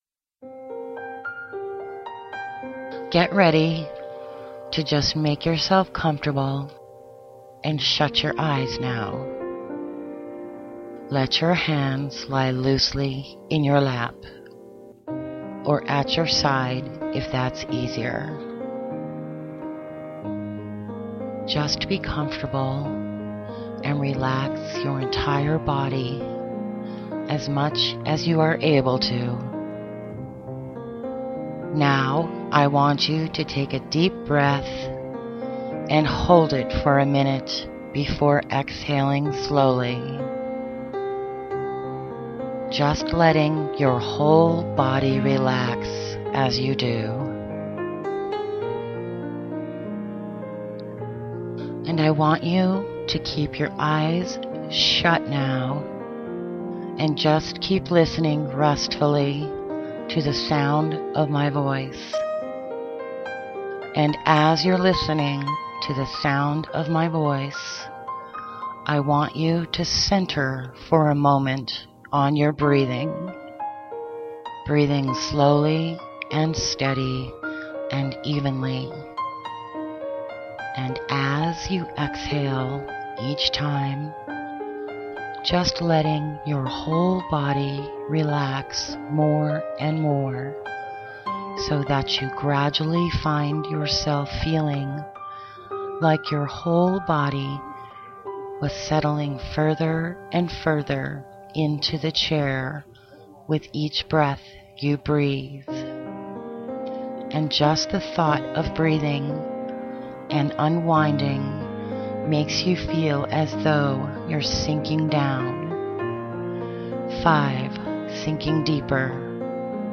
Hypnotherapy - Hypnosis Audio